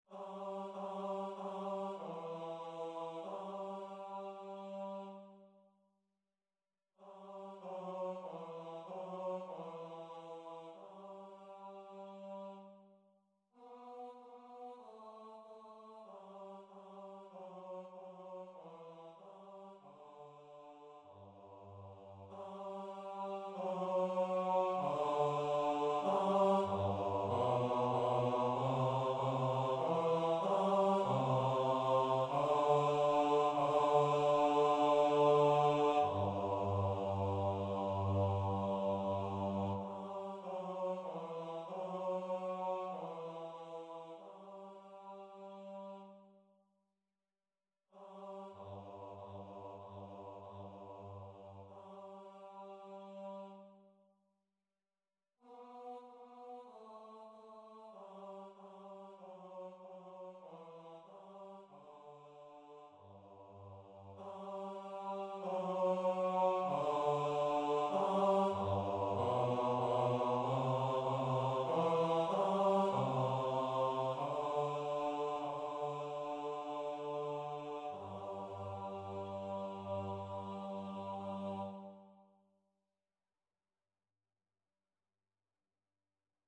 TRUE-LOVE-BASS.mp3